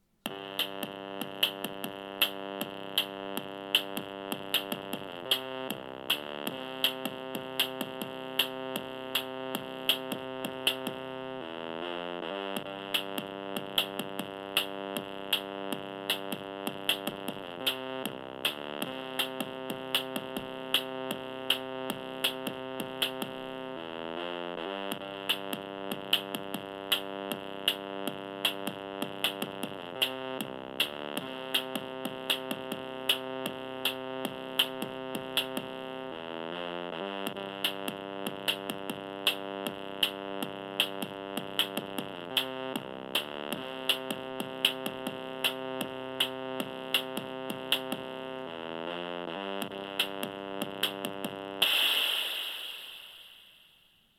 It’s a synth, looper, and MIDI controller that lets anyone make music immediately.
I’ve found the easiest way to approach it is laying down a rhythm track with the drums (to the built-in metronome) and then layering chords over that. Here’s a Day One attempt.
song-1-orbi.mp3